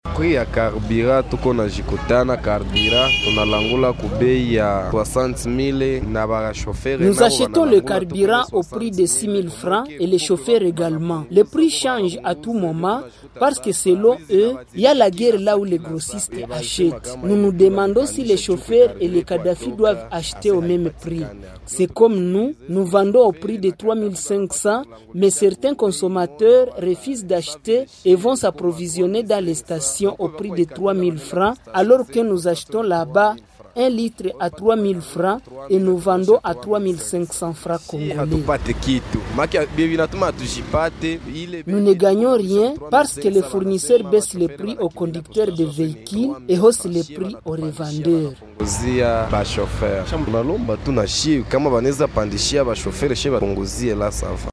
L’un des revendeurs du carburant en parle